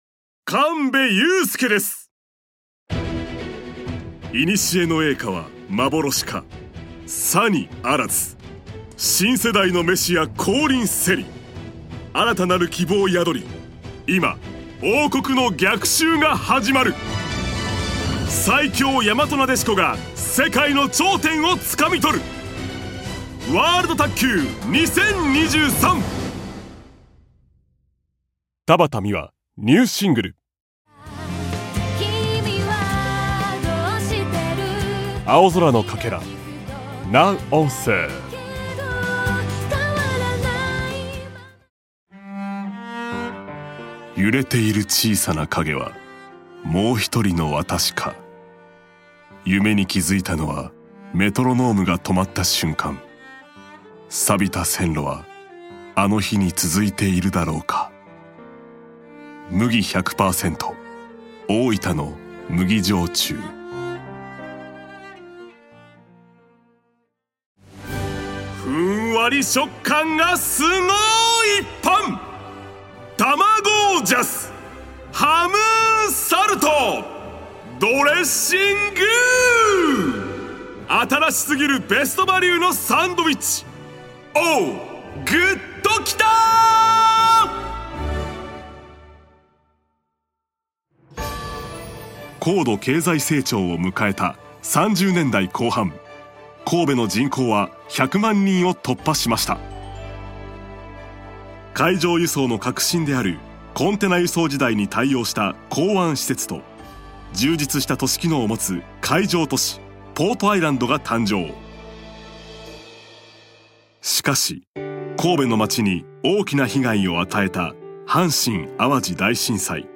Self Introduction
バリトンの声から4オクターブ以上の声域でCMナレーションも多数担当。